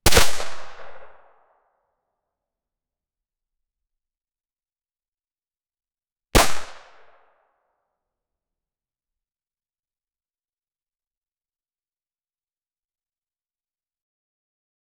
glock-17-9mm-caliber-sing-fgikofxb.wav